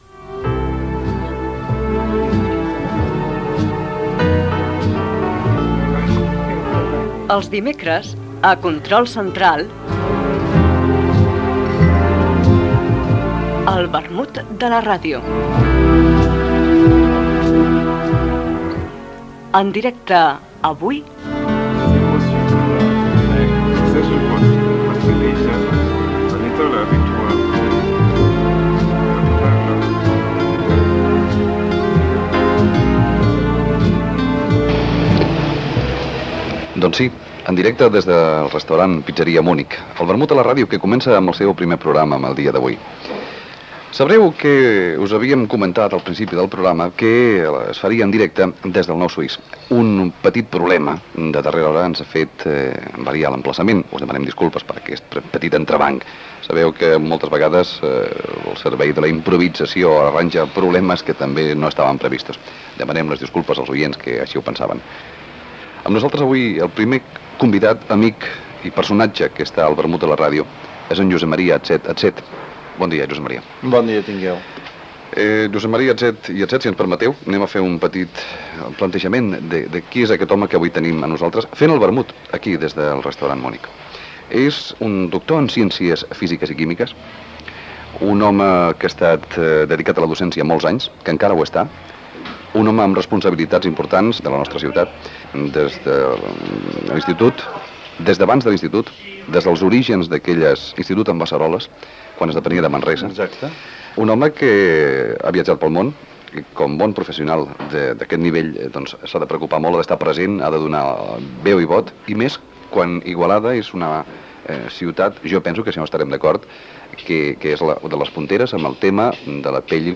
Espai fet en directe des del Bar Pizzeria Munich d'Igualada.
Gènere radiofònic Entreteniment